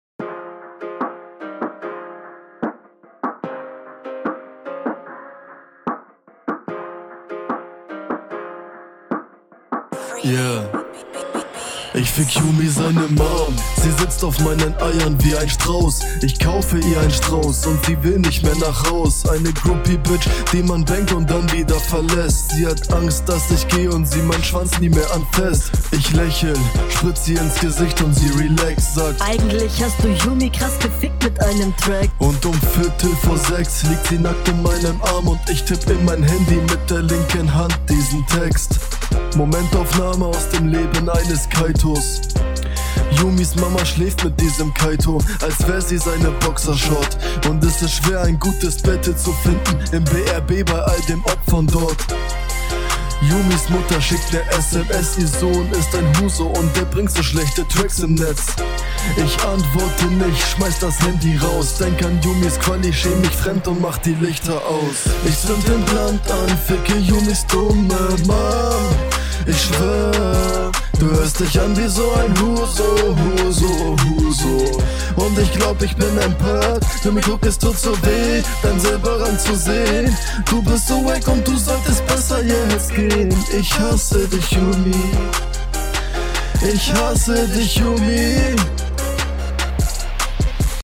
Flow: Passt vom Takt her aber insgesamt find ich das recht langweilig was hier passiert.